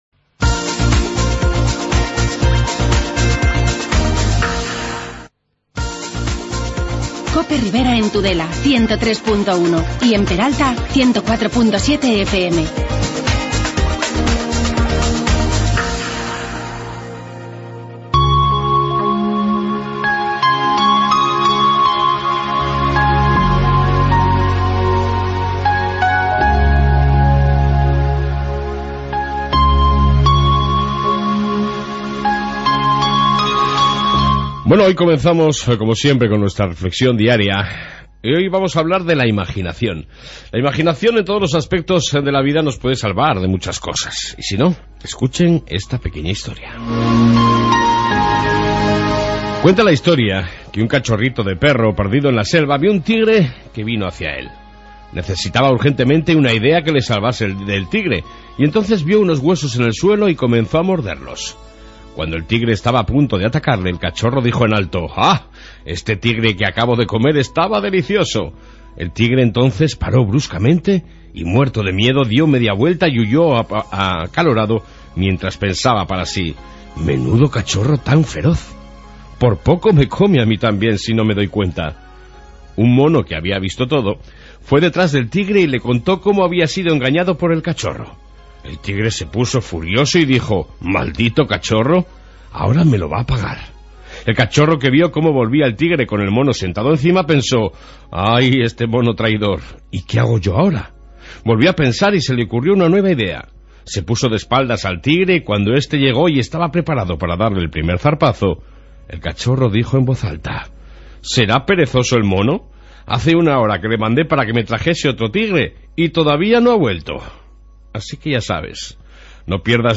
AUDIO: Reflexión matutina y entrevista con la concejal de Bienestar Social de Tudela Marisa Marqués